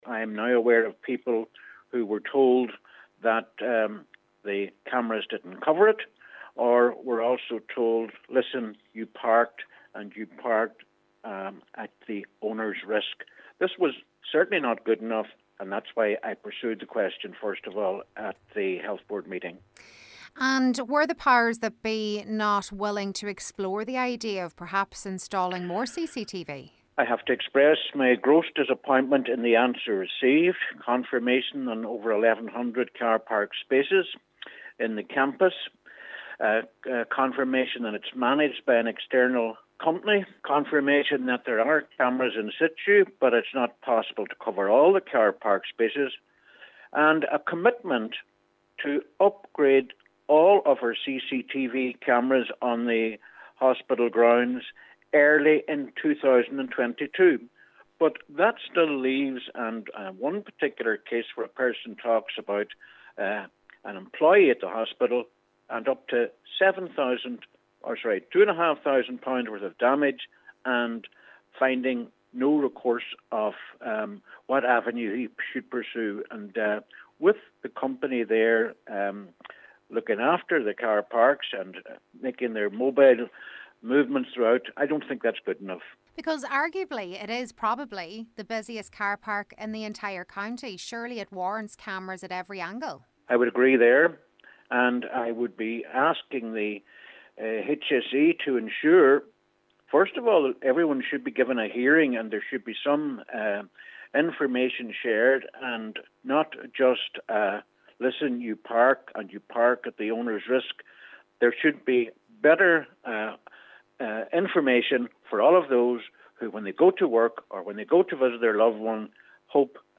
However, Cllr Doherty says that is not good enough: